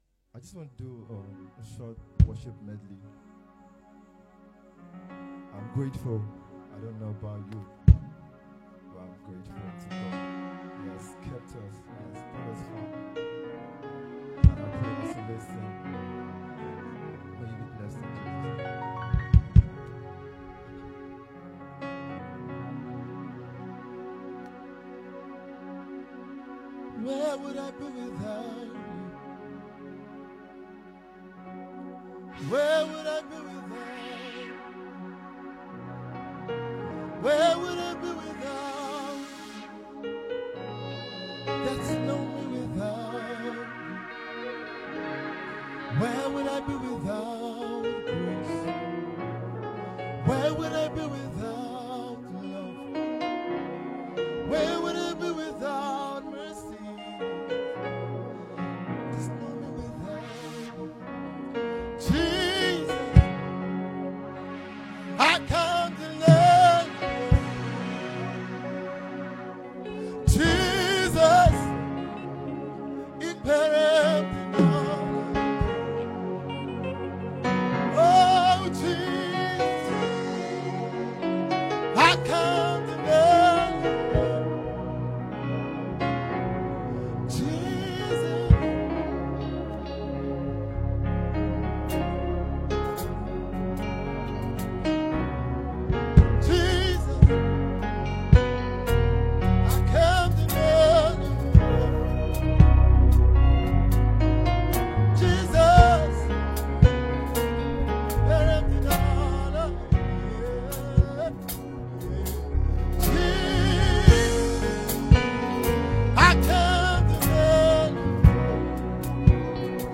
a song that was sang through a live performance
a song of hope full of courage